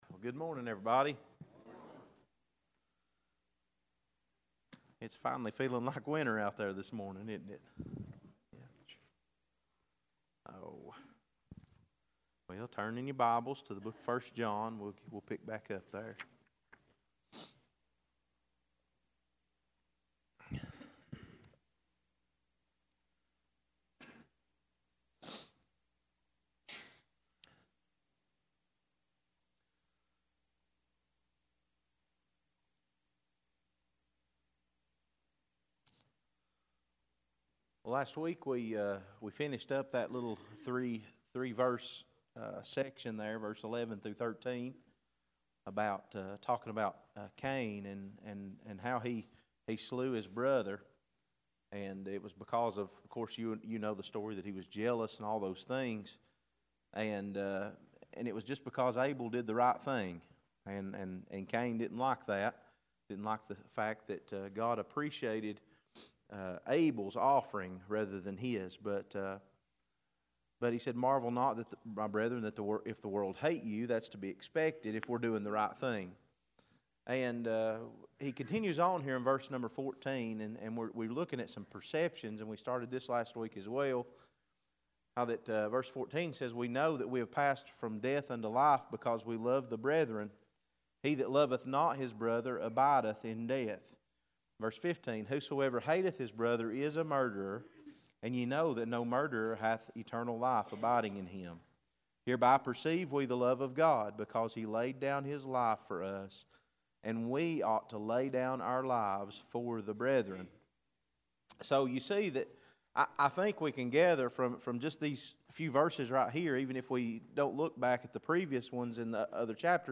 Passage: 1 John 3: 14-16 Service Type: Sunday School